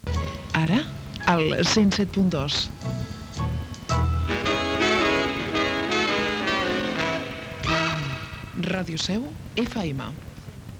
fa0e42dffa67b1bcb7f6f45a0fcf070c0cd56dc1.mp3 Títol Ràdio Seu FM Emissora Ràdio Seu FM Titularitat Pública municipal Descripció Identificació i freqüència.